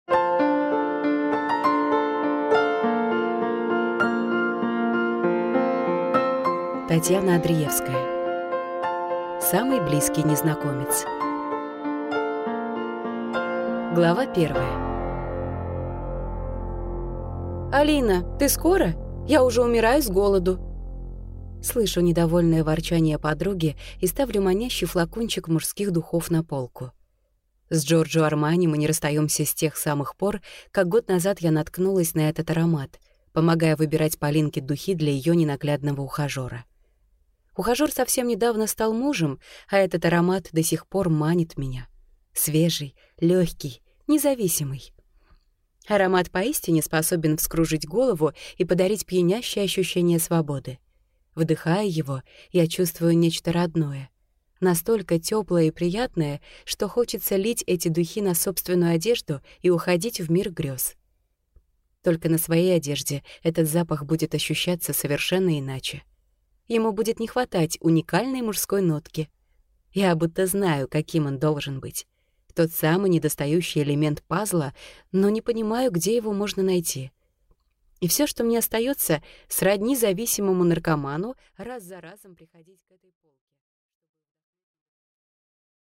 Аудиокнига Самый близкий незнакомец | Библиотека аудиокниг